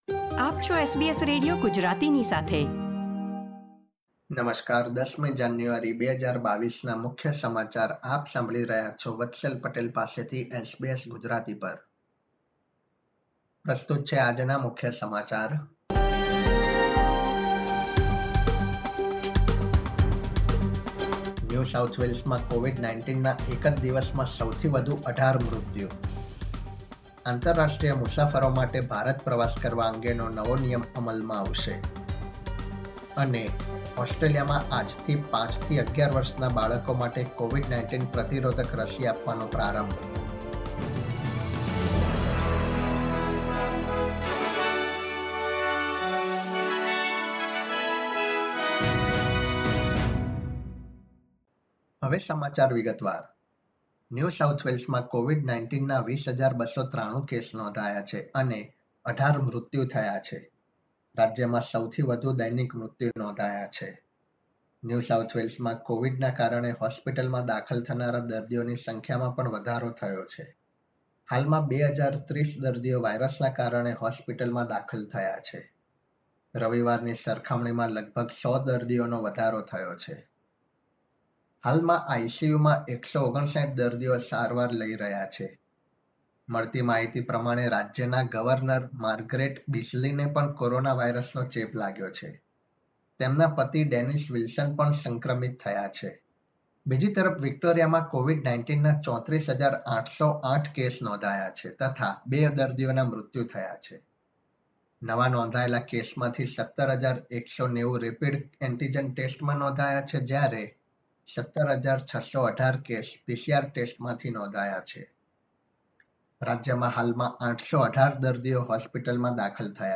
SBS Gujarati News Bulletin 10 January 2022